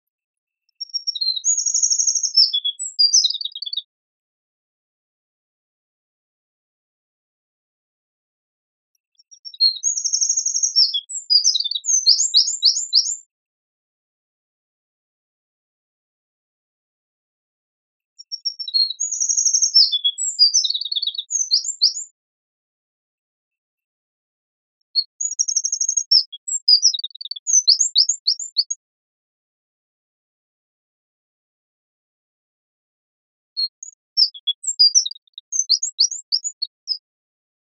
ミソサザイのさえずりは、短い「ピピッ、ピピッ」という高い音が特徴的です。
ミソサザイのさえずり 着信音
この鳥のさえずりは、主に高く響く「ピューピュー」という鳴き声が特徴的です。